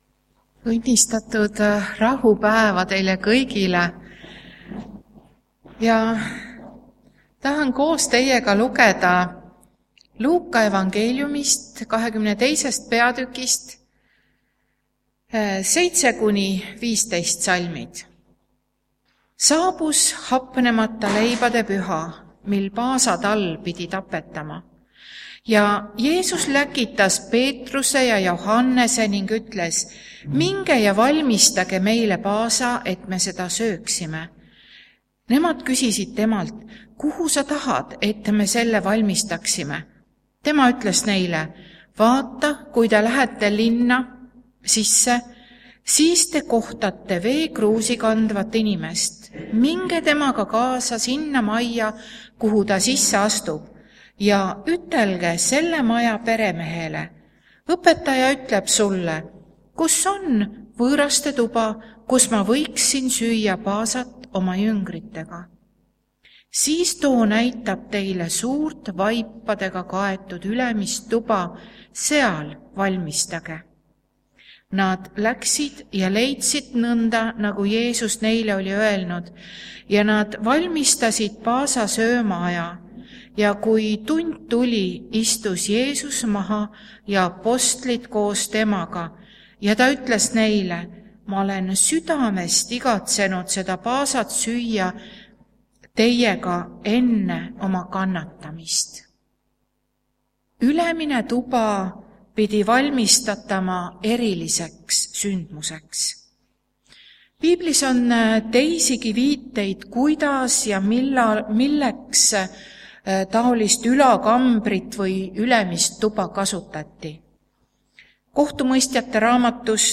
Täna on meil osasaamise koosolek. Ja jutluse teemaks PAASATALL (Jeesus)
Jutlused